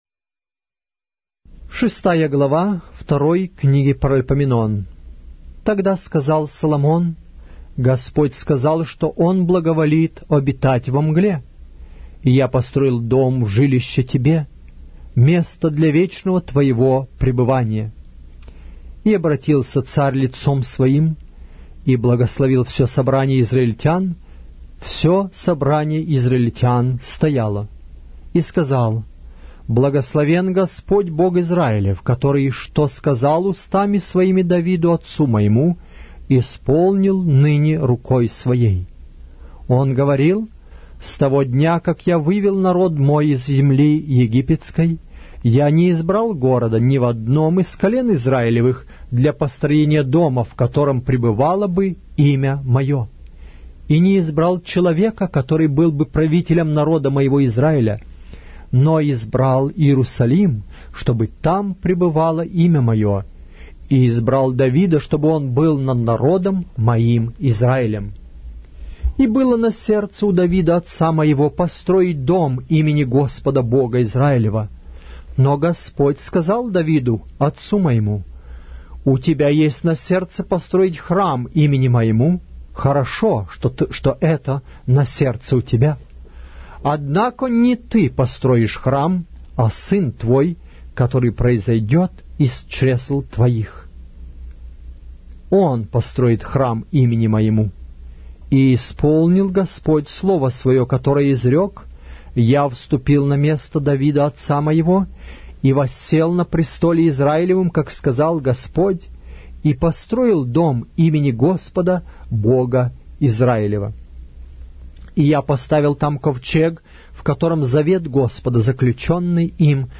Автор аудиокниги: Аудио - Библия